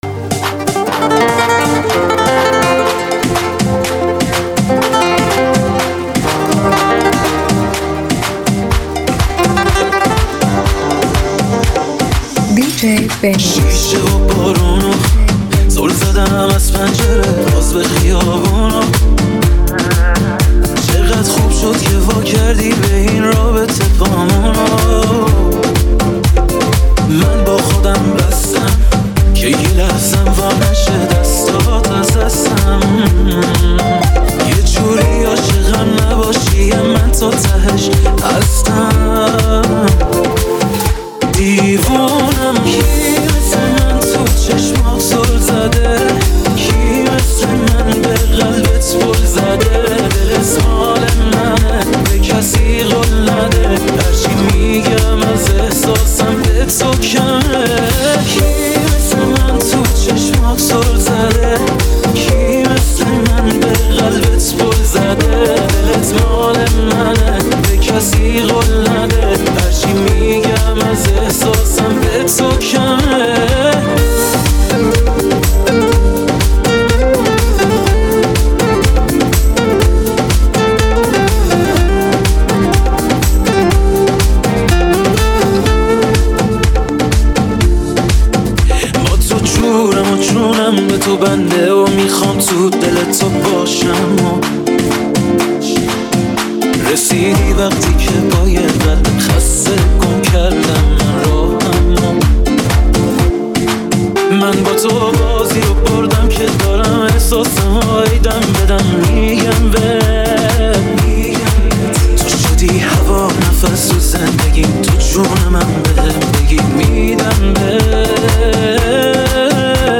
اوج احساس و هیجان را در این قطعه عاشقانه تجربه کنید.